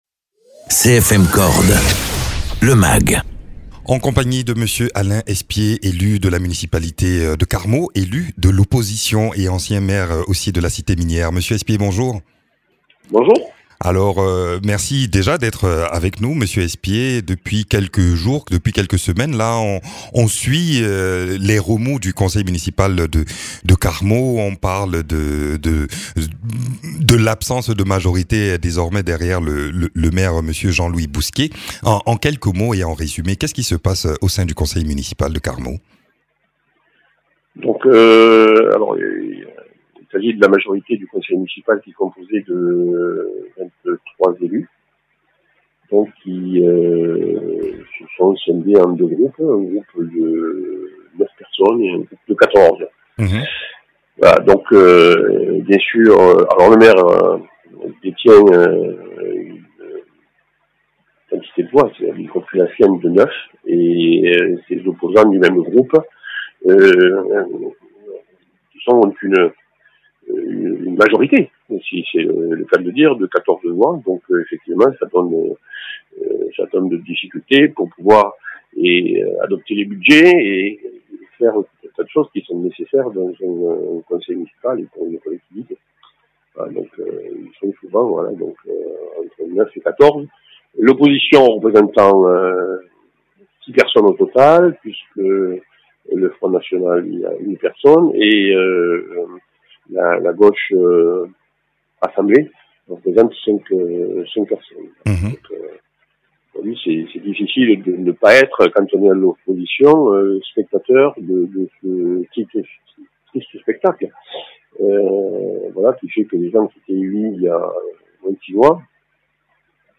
Interviews
Invité(s) : Alain Espié, élu de l’opposition du conseil municipal de Carmaux.